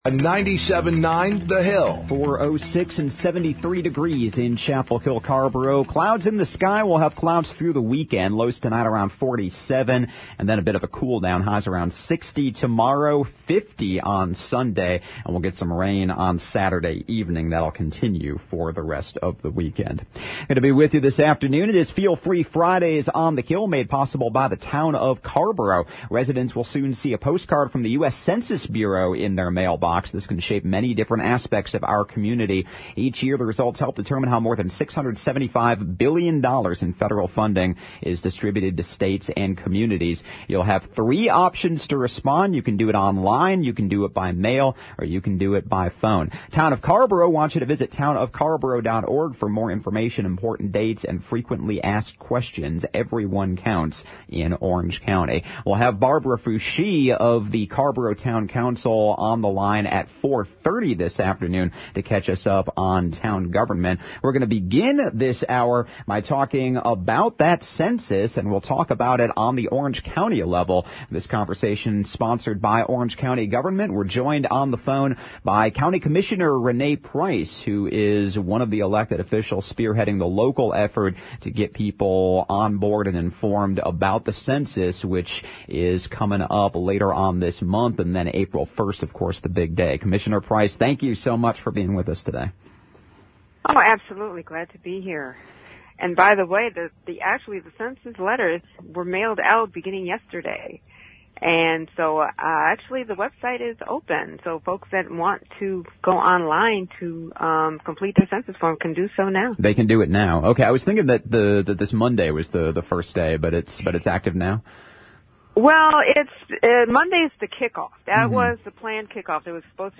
by phone to discuss the impact the Census will have on local governments. Catch up on their conversation: